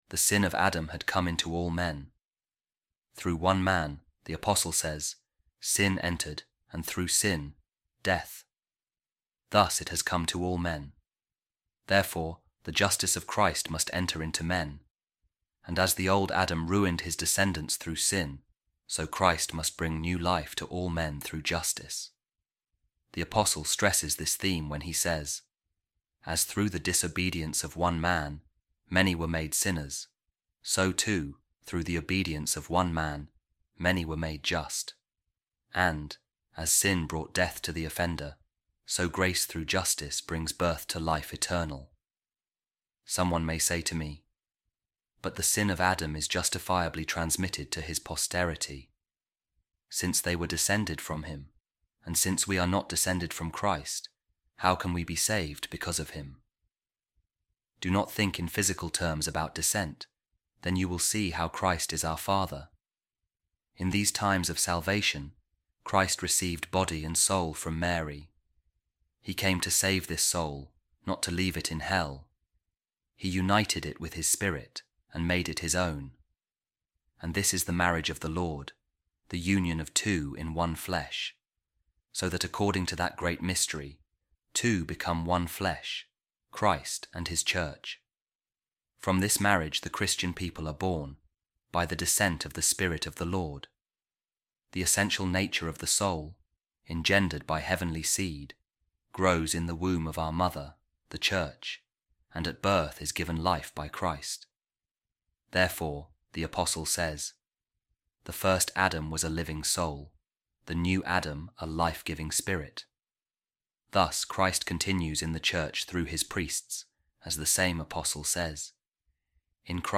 A Reading From The Sermon Of Saint Pacian On Baptism | A New Christian Morality | Holy Spirit Of Jesus Christ